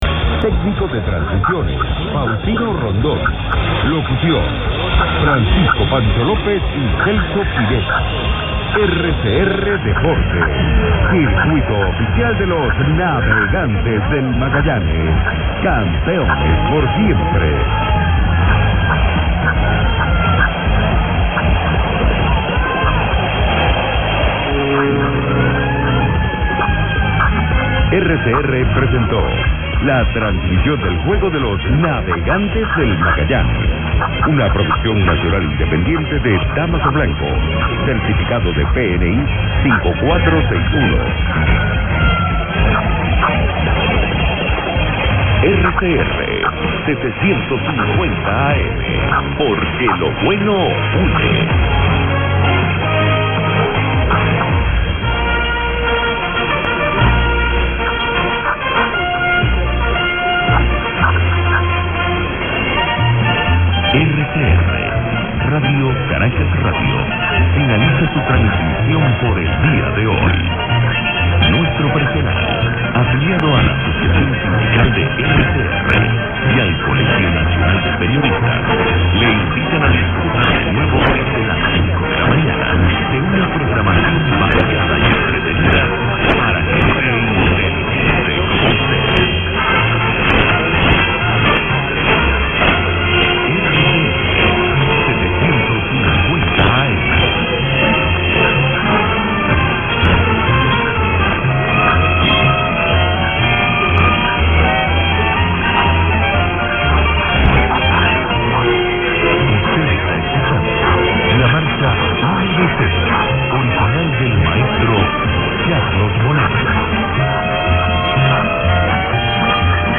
RCR 750 Caracas, Ven 0457 good ids and anns 5/11